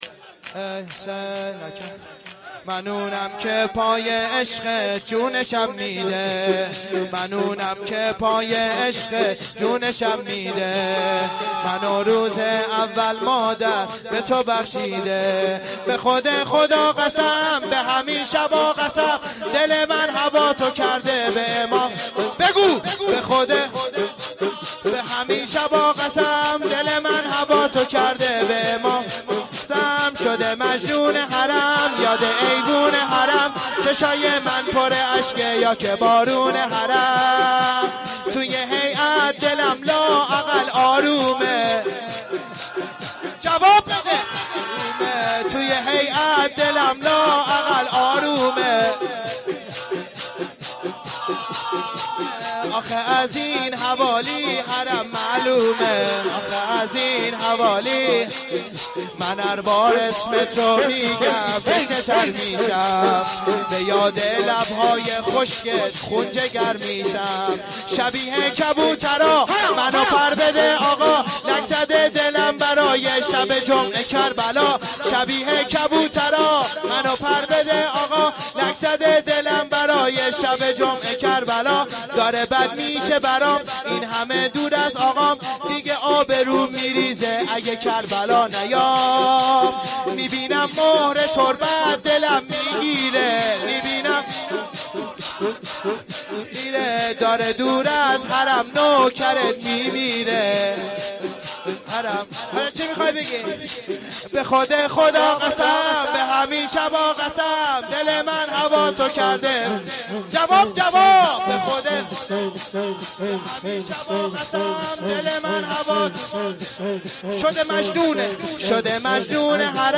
روضه العباس